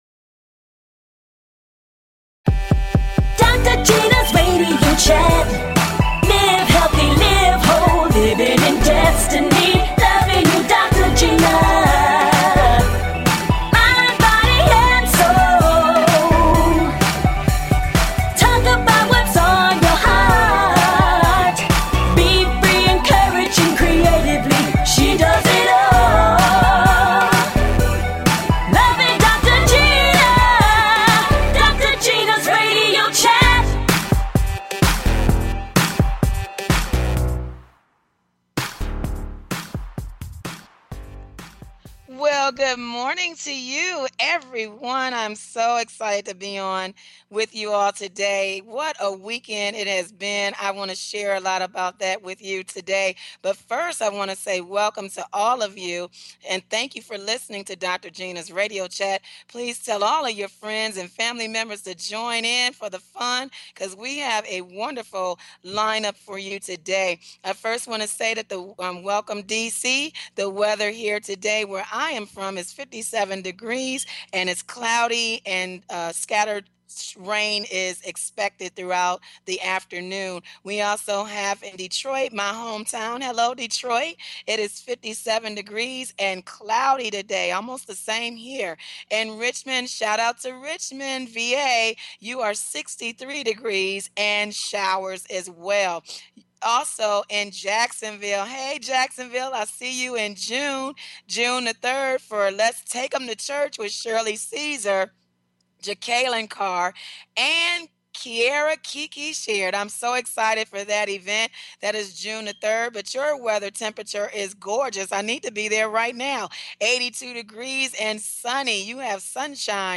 Talk Show Episode, Audio Podcast
Guests include: Comedian